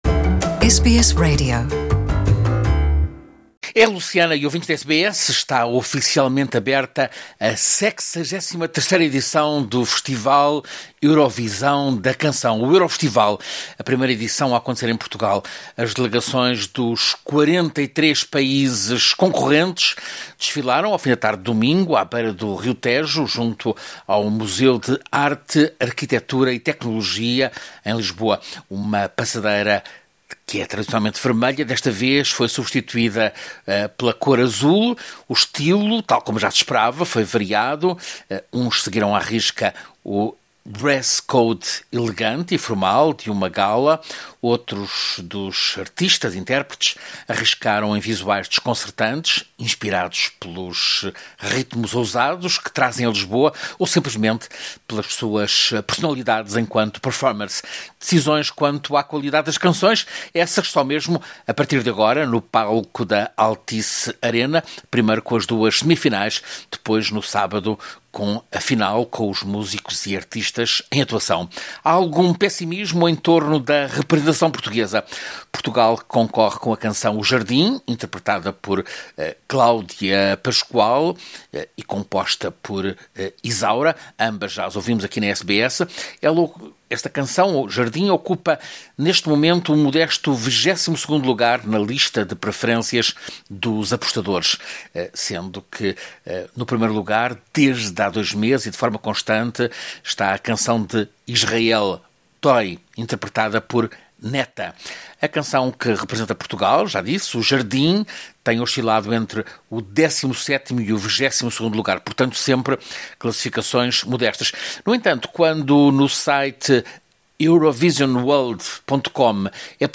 Lisboa, anfitriã do Festival da Canção Eurovisão 2018, foi invadida por mais de 30 mil estrangeiros vindos de 109 países. Ouça reportagem